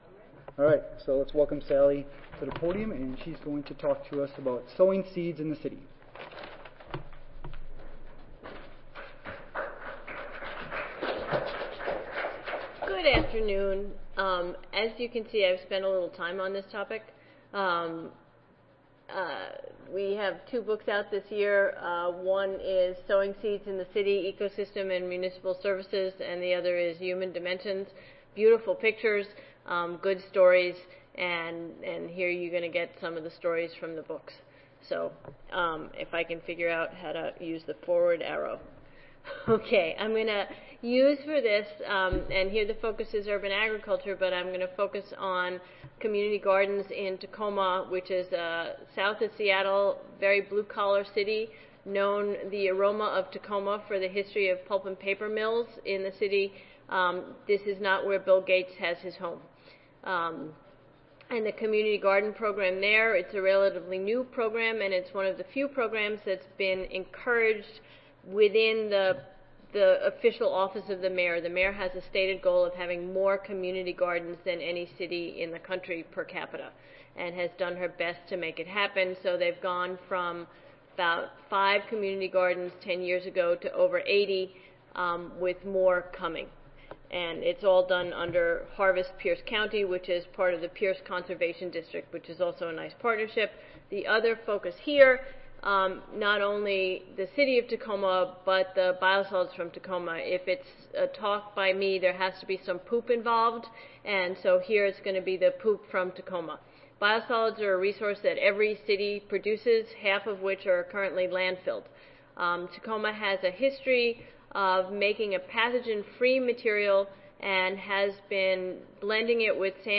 Session: Symposium--Optimizing Ecosystem Services of Urban Soils: Our Current State of the Science (ASA, CSSA and SSSA International Annual Meetings (2016))
University of Alaska Audio File Recorded Presentation